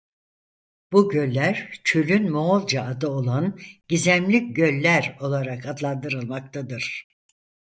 Pronounced as (IPA)
[moːɫdʒɑ]